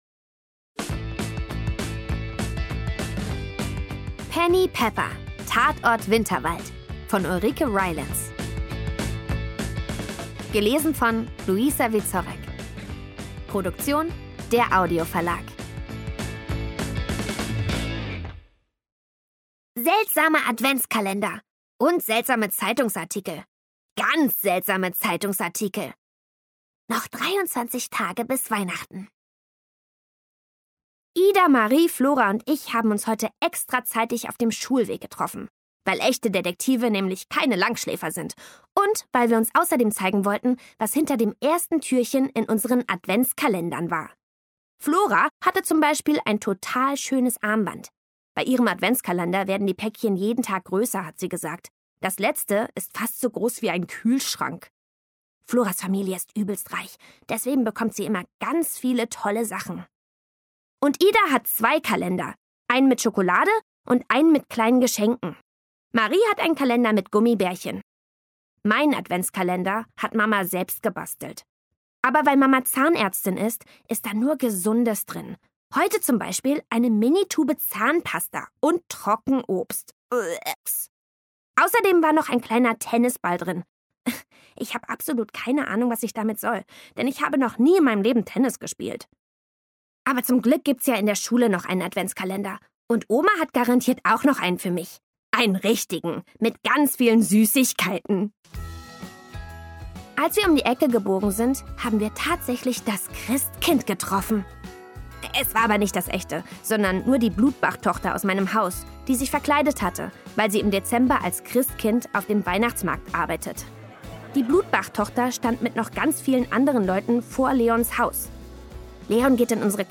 Szenische Lesung mit Musik